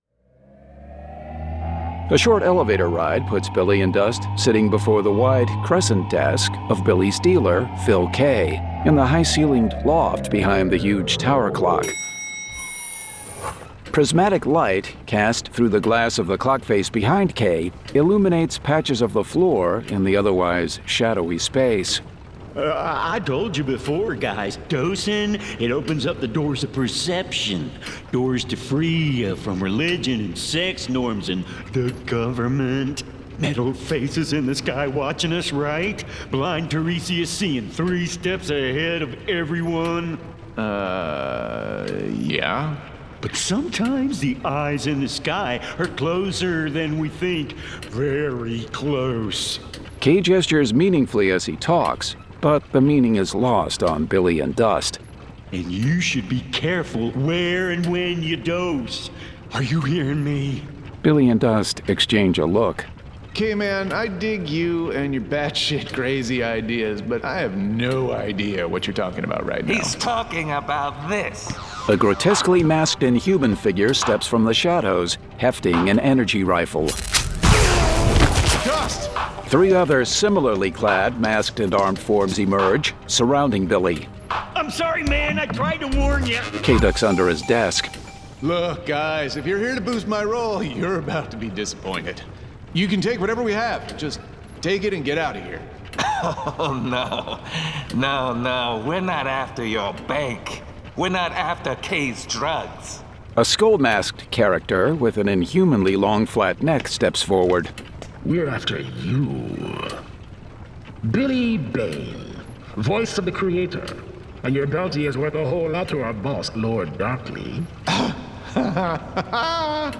Full Cast. Cinematic Music. Sound Effects.
[Dramatized Adaptation]
Adapted directly from the graphic novel and produced with a full cast of actors, immersive sound effects and cinematic music.